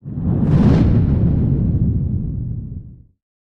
sfx_car_fly.mp3